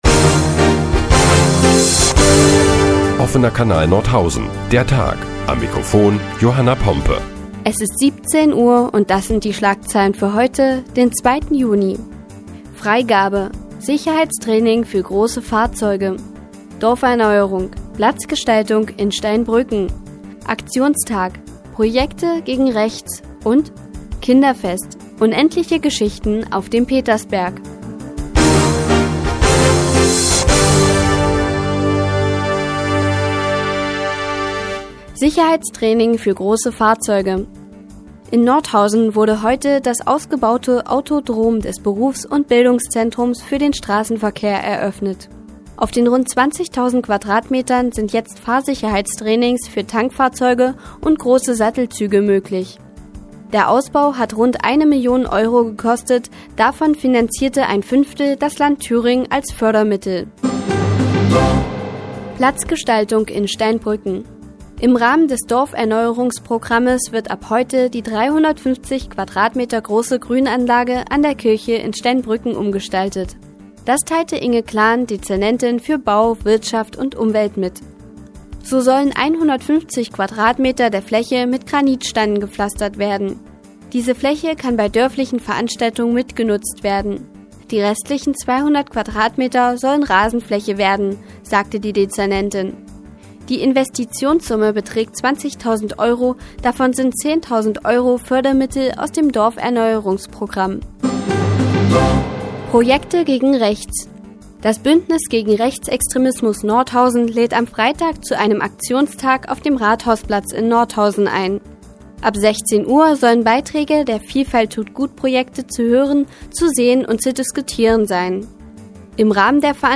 Die tägliche Nachrichtensendung des OKN ist nun auch in der nnz zu hören. Heute geht es unter anderem um die Platzgestaltung in Steinbrücken und Projekte gegen Rechts.